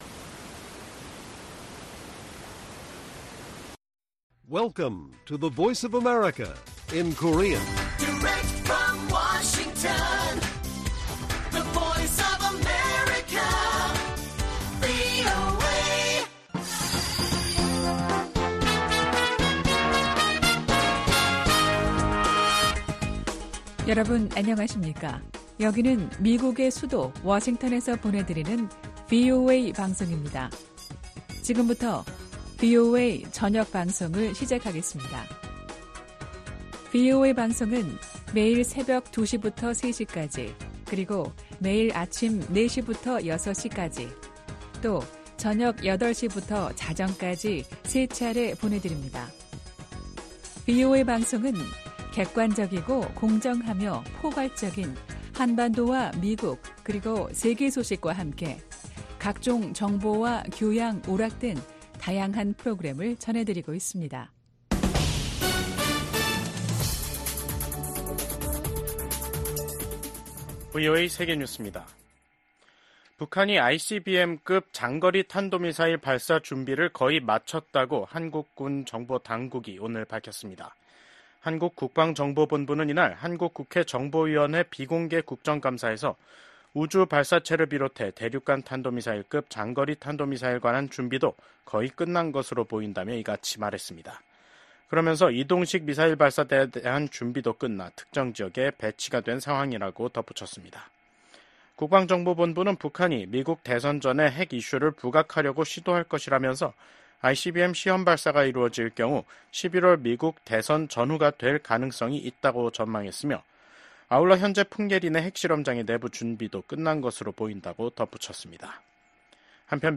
VOA 한국어 간판 뉴스 프로그램 '뉴스 투데이', 2024년 10월 30일 1부 방송입니다. 미국 국방부는 러시아에 파견된 북한군 일부가 이미 우크라이나에 가까운 러시아 쿠르스크에 주둔 중이라고 밝혔습니다. 윤석열 한국 대통령은 볼로디미르 젤렌스키 우크라이나 대통령과 쥐스탱 트뤼도 캐나다 총리와 연이어 통화를 하고 북한의 러시아 파병에 대한 공동 대응 의지를 밝혔습니다.